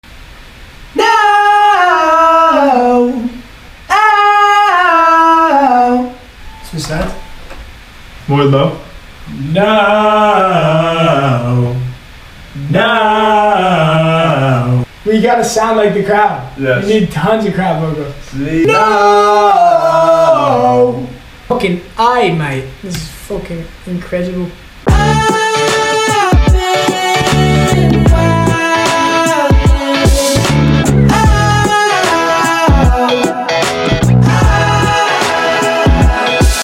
recording vocals for “Good Vibe